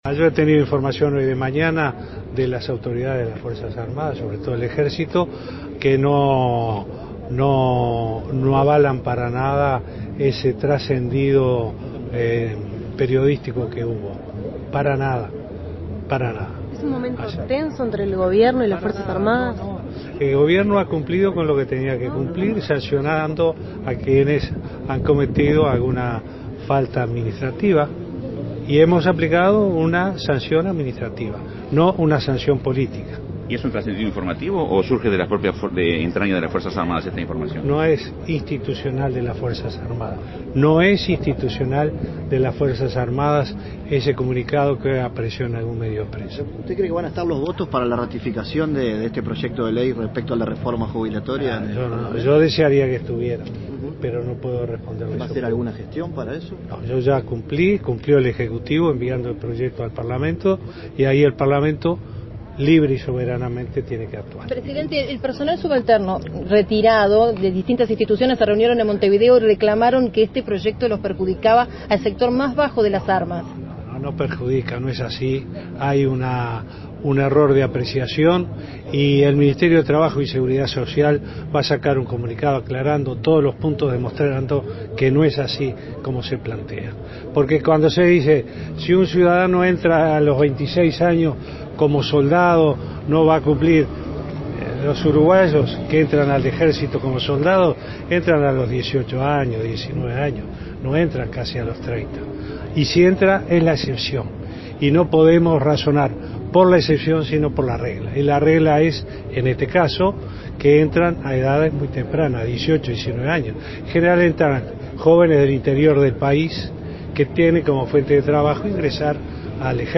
El presidente de la república, Tabaré Vázquez, habló de la relación de las Fuerzas Armadas y el gobierno.